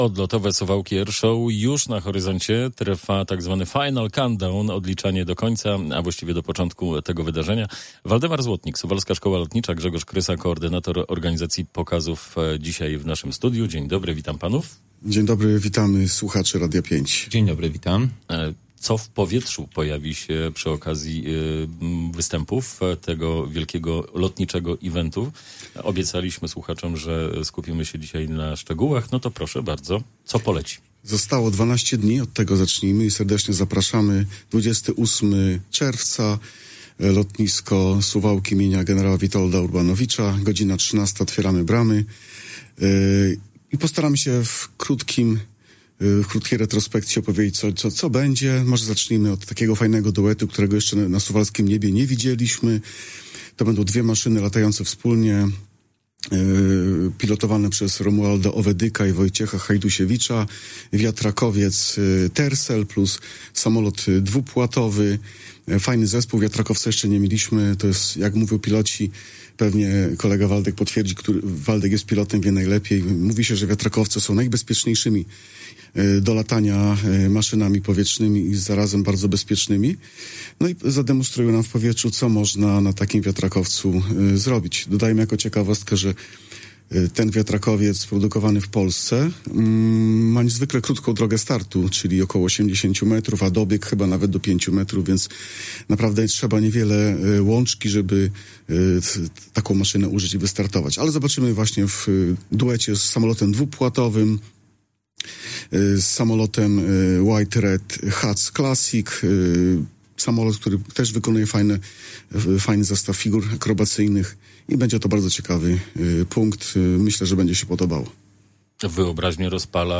Na tegoroczną edycją Odlotowych Suwałk Air Show zapraszali w poniedziałek (16.06) w Radiu 5